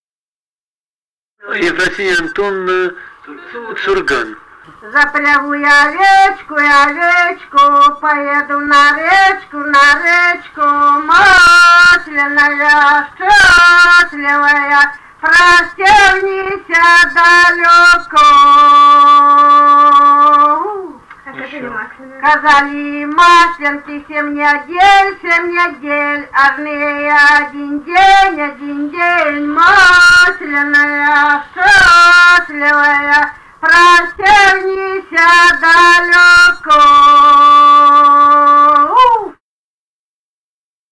Музыкальный фольклор Климовского района 037. «Запрягу я бугая» (масленая).
Записали участники экспедиции